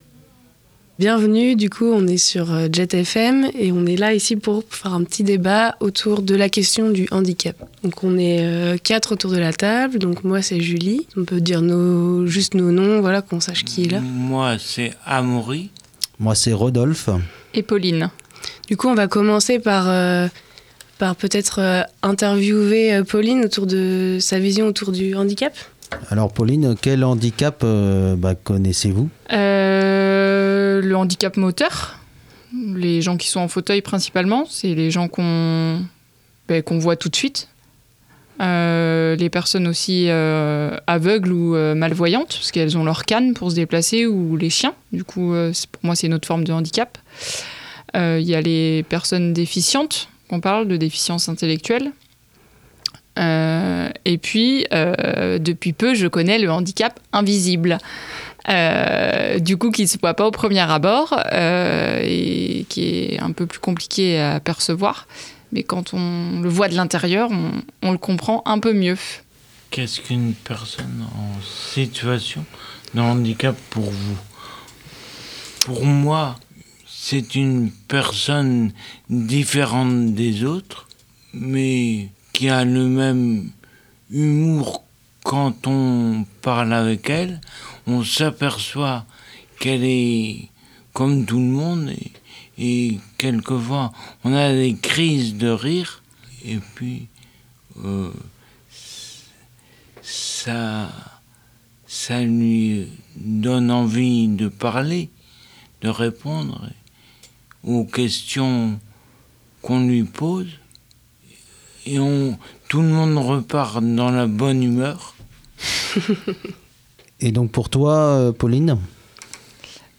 Pour cette 7ème émission, nous avons laissé la place à un groupe d’auto-représentation de personnes en situation de handicap
Il.elle.s sont allé.e.s interviewer des personnes sur leur vision du handicap, et, pendant une heure, il.elle.s vous racontent et témoignent de ce qu’il.elle.s vivent au quotidien : le regard sur le handicap, l’accessibilité des lieux publics, des loisirs, etc. Tout ceci rythmé par des morceaux de musique.
mp3_Studio_-Interview-debat_handicap_accessibilite.mp3